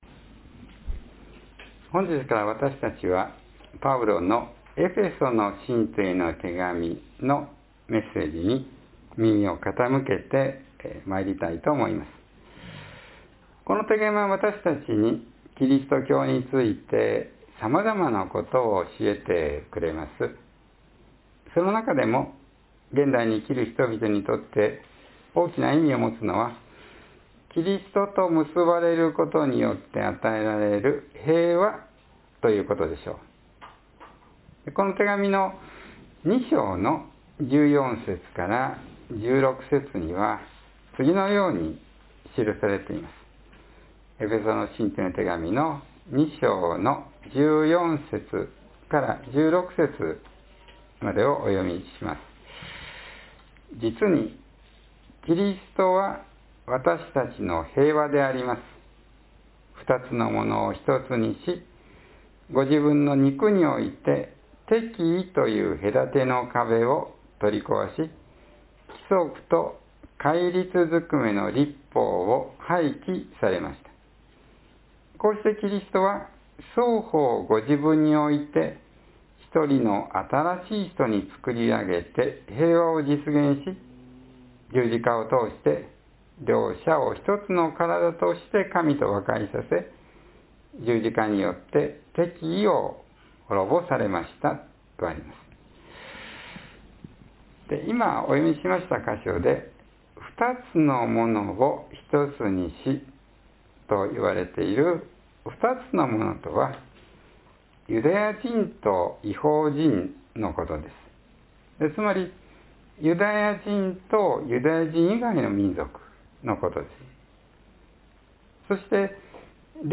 （11月3日の説教より） Starting today we will listen to the message of Paul’s letter to the Ephesians.